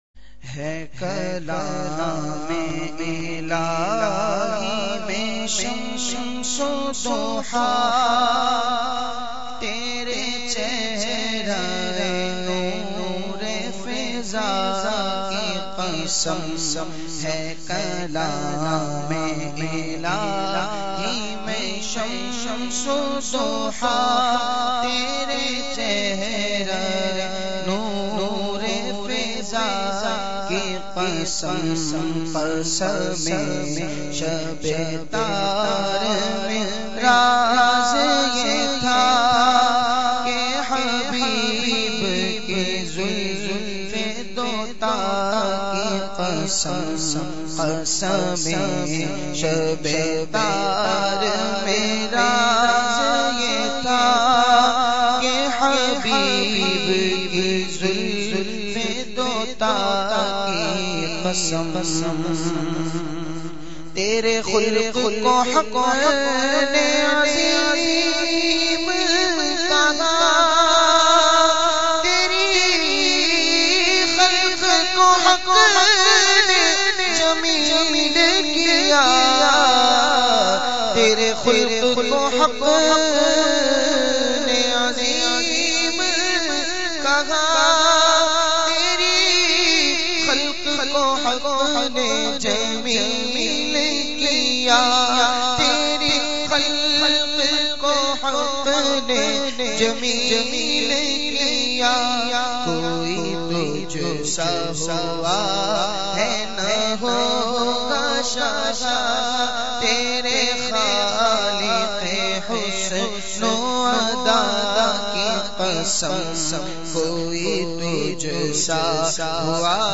The Naat Sharif
نعت